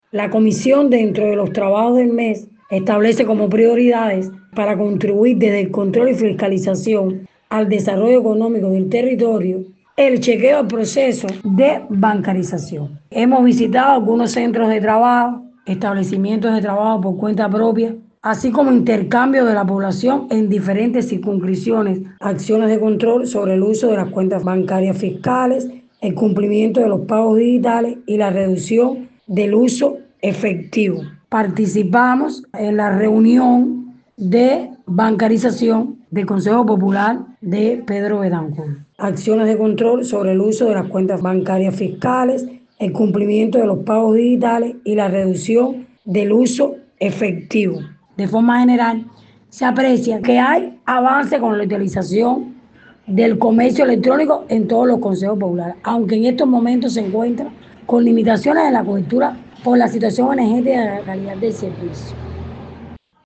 En Pedro Betancourt, el proceso de bancarización se consolida como prioridad en la agenda económica municipal. Desde su rol como presidenta de la Comisión Permanente de Asuntos Económicos de la Asamblea del Poder Popular, Taimí García Cartaya compartió con nuestra emisora una mirada sobre el alcance de esta transformación y los desafíos que implica para la gestión local.